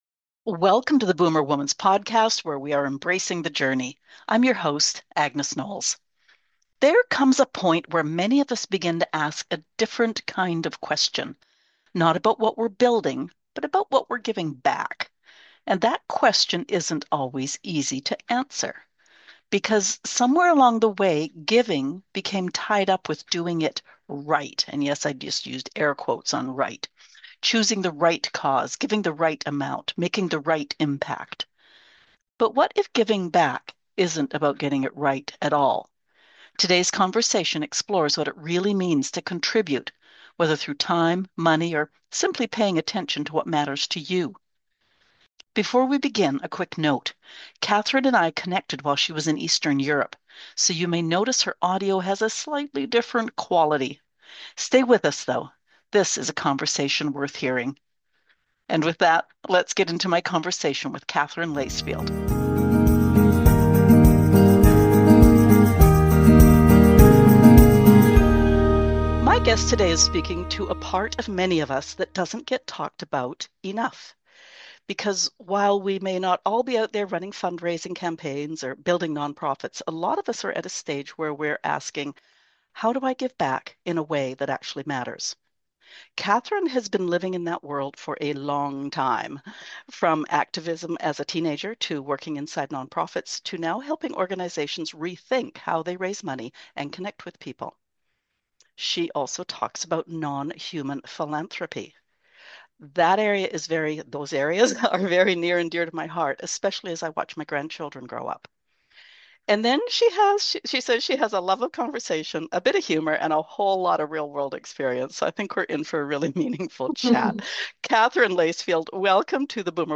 A thoughtful, eye-opening conversation about giving back—how to do it in a way that feels meaningful, sustainable, and truly impactful, without getting lost in guilt or confusion.